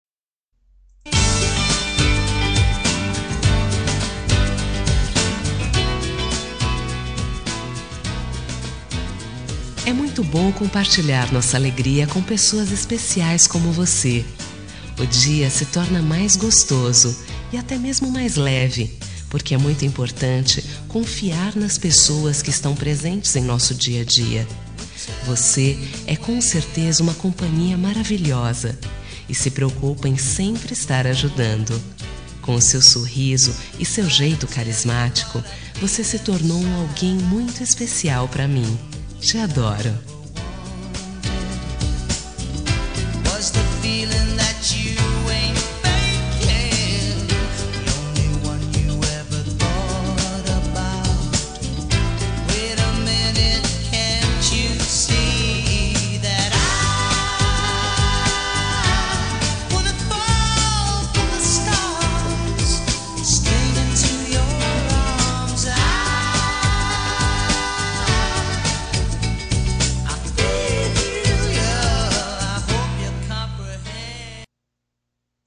Telemensagem de Amizade – Voz Feminina – Cód: 93
93-amizade-fem.m4a